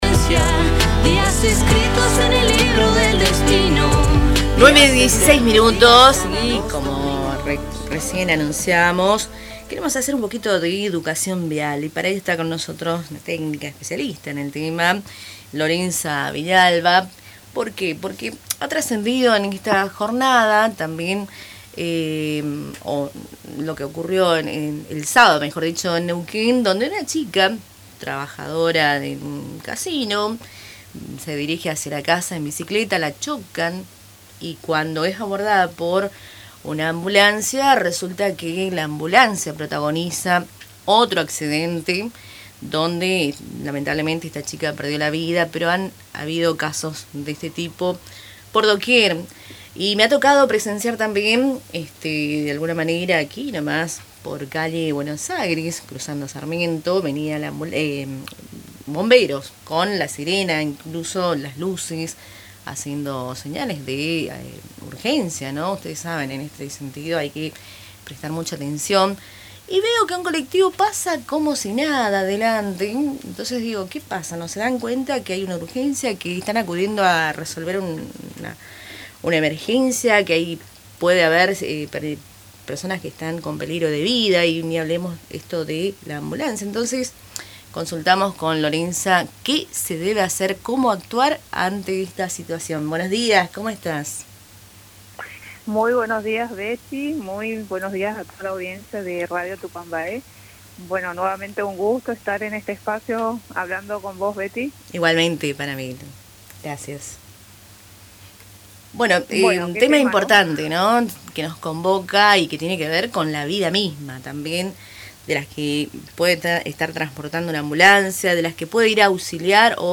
En diálogo con Radio Tupa Mbae